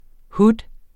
Udtale [ ˈhud ]